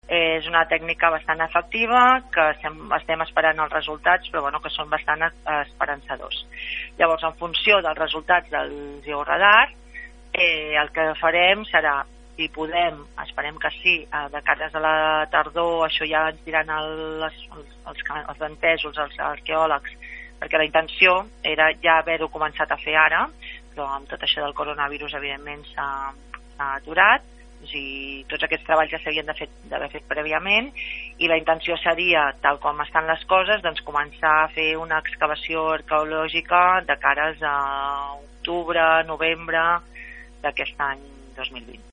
Olga López, tinent d’alcalde de l’Arxiu Municipal de Blanes, ha explicat a RP que la prospecció amb georadar és el pas previ a l’excavació en extensió a tota la zona, prevista per aquesta mateixa tardor, i n’esperen resultats positius.